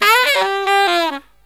63SAXMD 09-R.wav